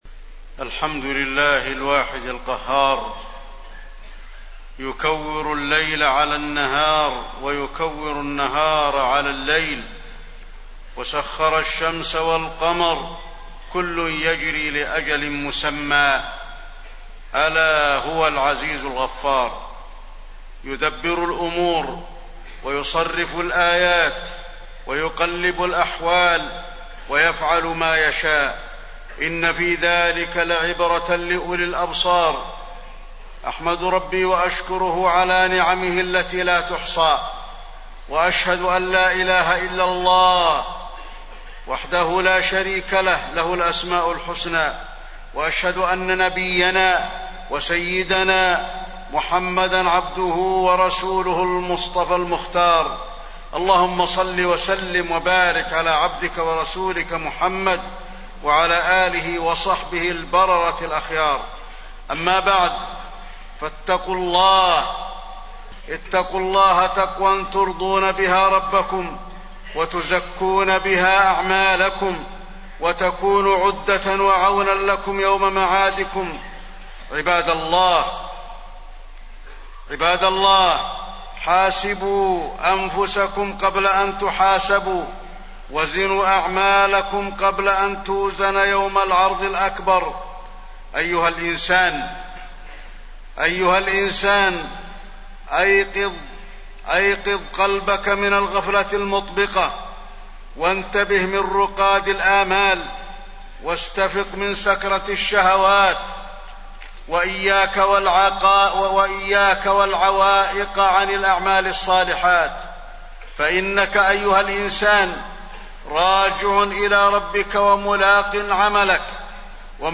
تاريخ النشر ١ محرم ١٤٣١ هـ المكان: المسجد النبوي الشيخ: فضيلة الشيخ د. علي بن عبدالرحمن الحذيفي فضيلة الشيخ د. علي بن عبدالرحمن الحذيفي ابتداء العام الجديد بمحاسبة النفس